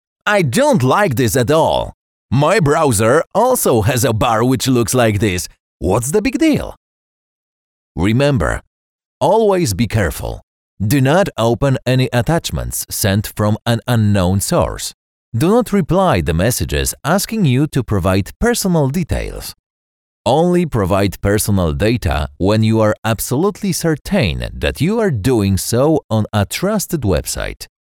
Male 30-50 lat
Nagranie lektorskie